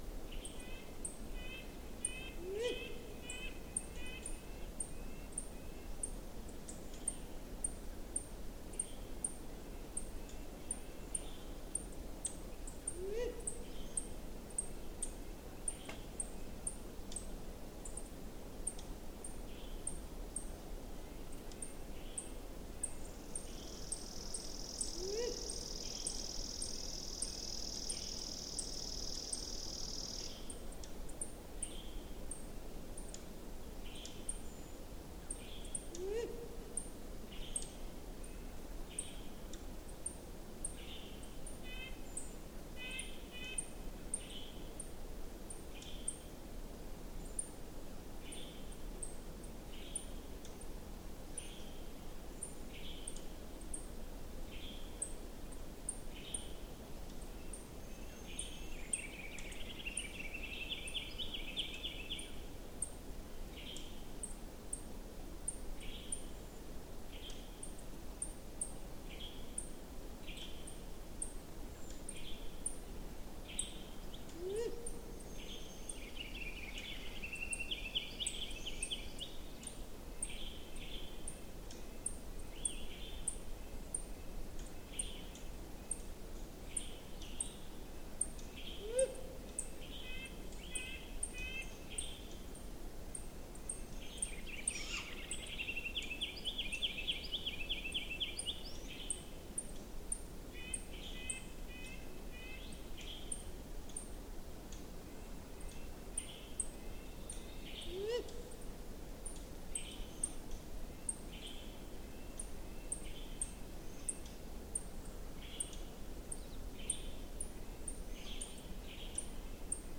These 2-minute samples were recorded side by side in June in a quiet but very vocal Montana forest.  The audio is unedited, except for normalizing the amplitude to more accurately compare the two signals.
80 dB SNR Electret Condenser Microphone